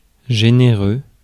Ääntäminen
US : IPA : /ˈæmpl/